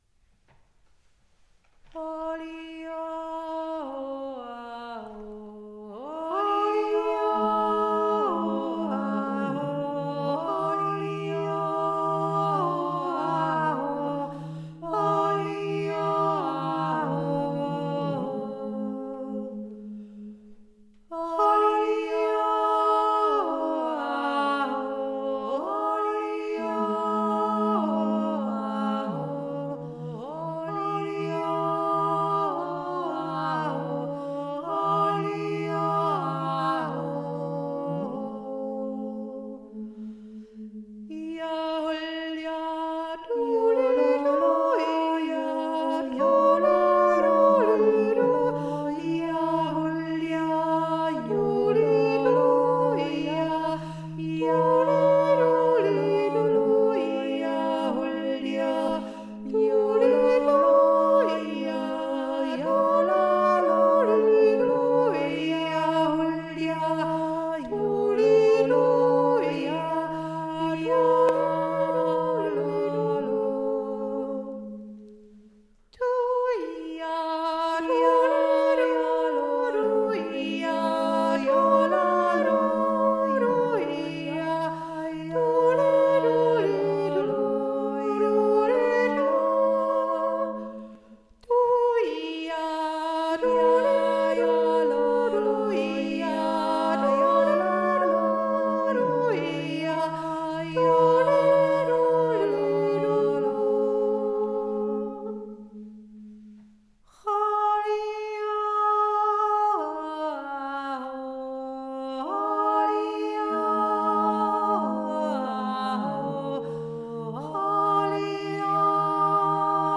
Z`unäsch im Neustüberl (schweizer traditionell - Appenzell)
ein Jodler der viel besser klingt, wenn ihn viele jodeln
zweistimmig mit Bass